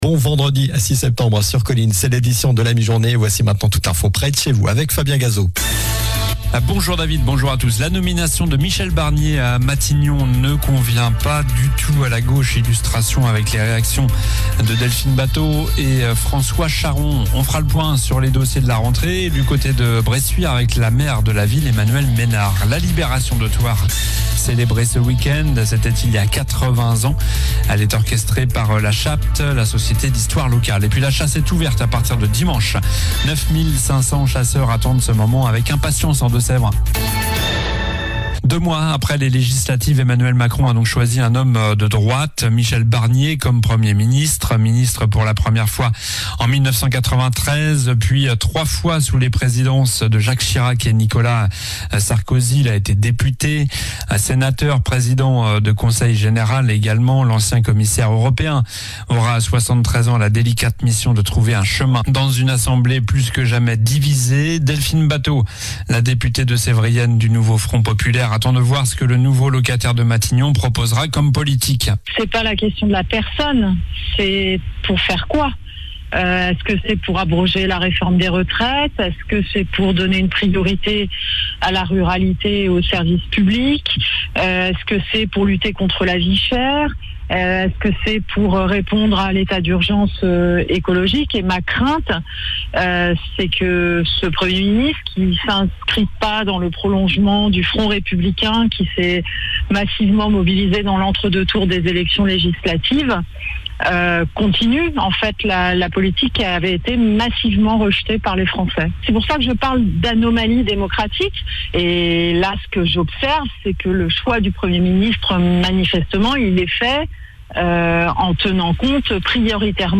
COLLINES LA RADIO : Réécoutez les flash infos et les différentes chroniques de votre radio⬦
Journal du vendredi 06 septembre (midi)